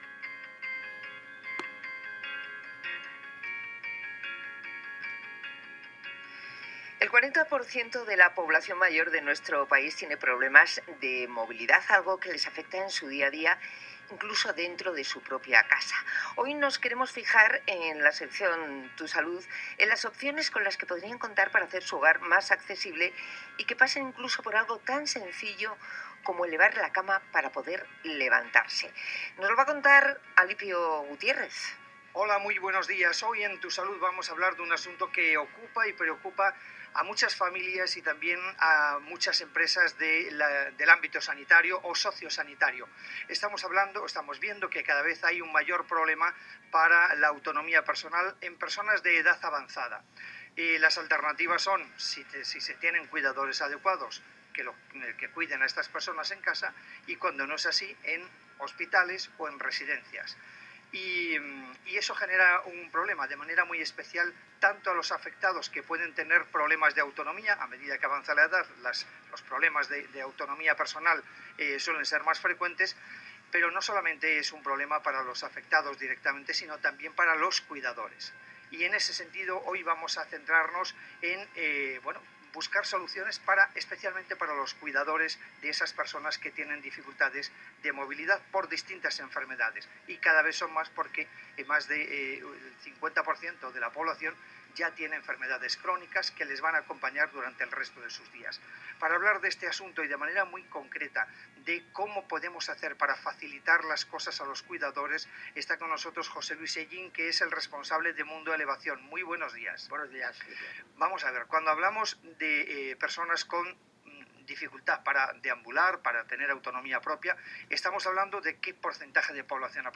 entrevista-levelit.m4a